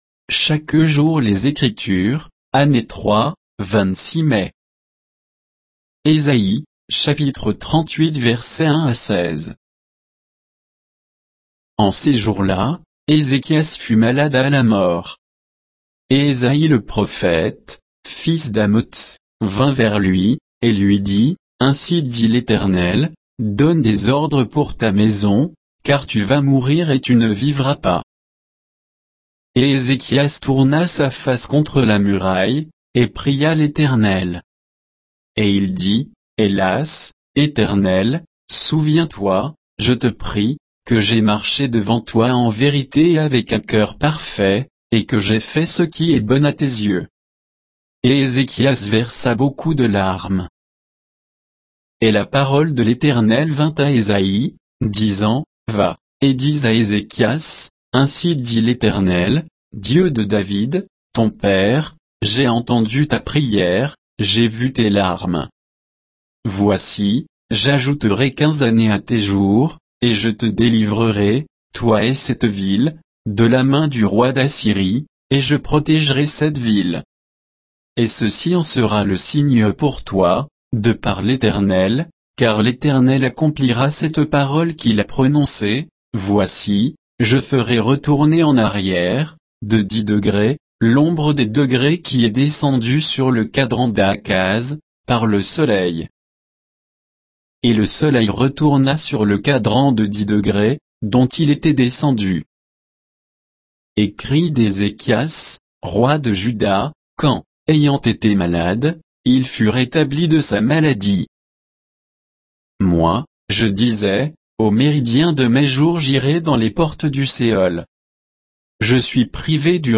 Méditation quoditienne de Chaque jour les Écritures sur Ésaïe 38